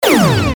Paradise/sound/weapons/pulse2.ogg
Added new weapon sounds: blaster, laser, pulse, wave, emitter, and one for the marauder canon.